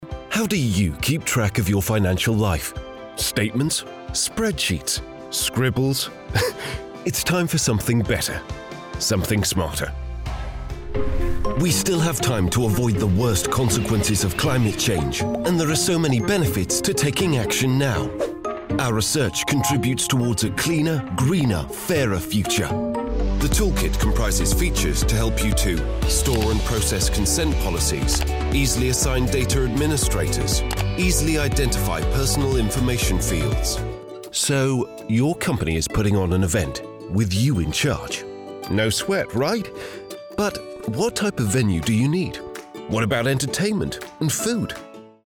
Inglés (Británico)
Profundo, Natural, Maduro, Cálida, Empresarial
Corporativo
Clear, professional and persuasive, it means hecan deliver your story with the utmost assurance and impact.